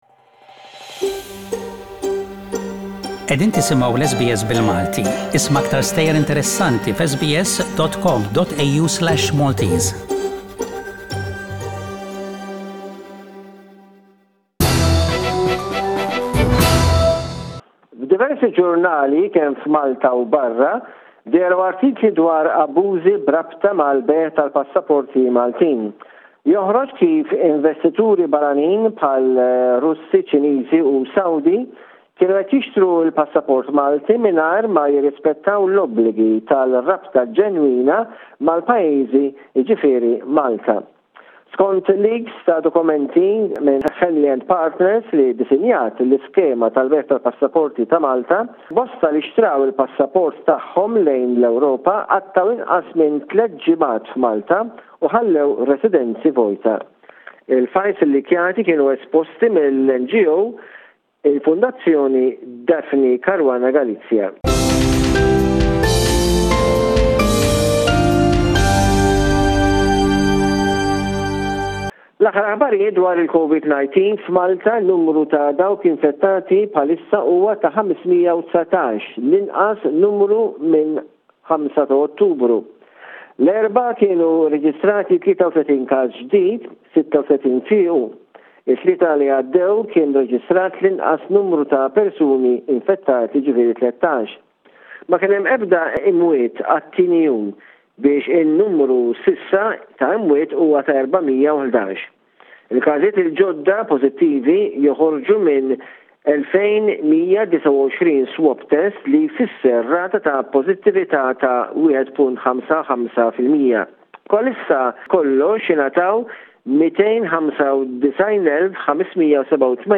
News from Malta: 23 April 21